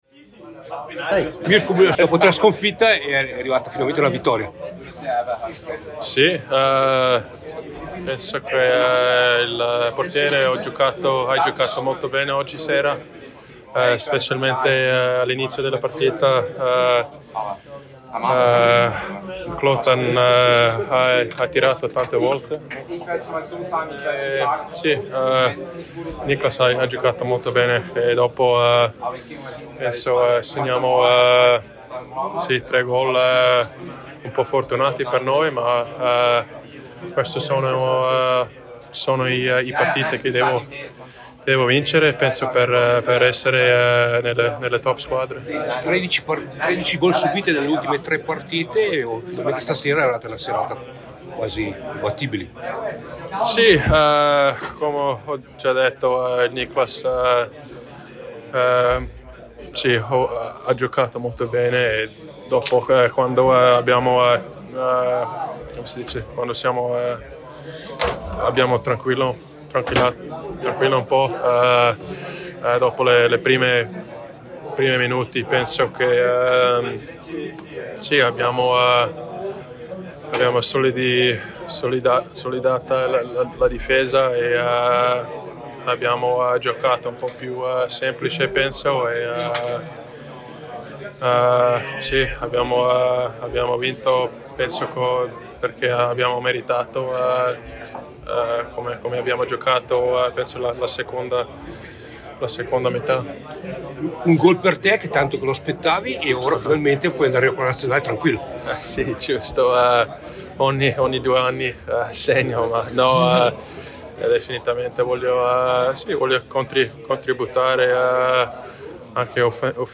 Interviste: